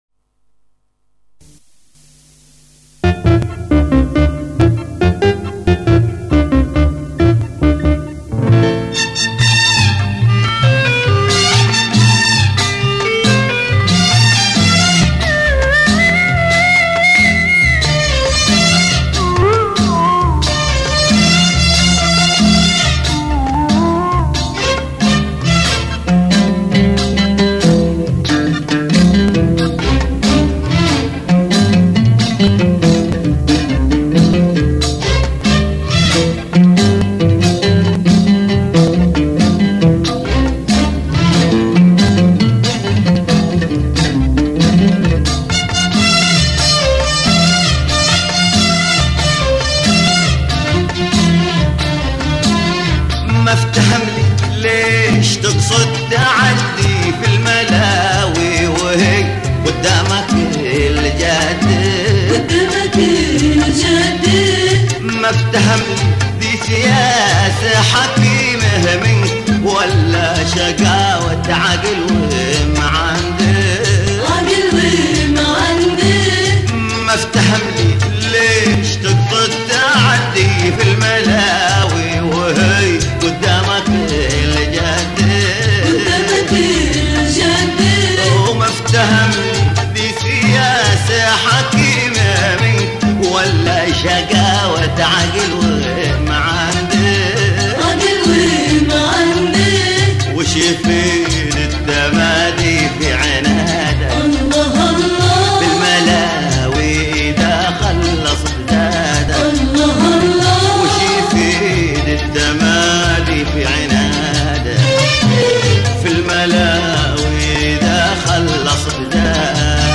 عرفته يحمل أزمنة في أثيره المرتعش.